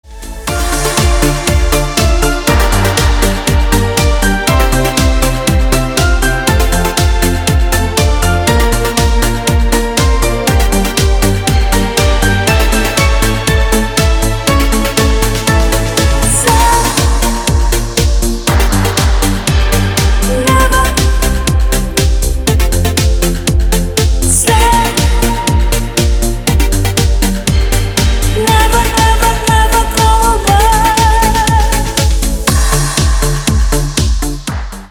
Рингтоны ремиксов на 80-е - Remix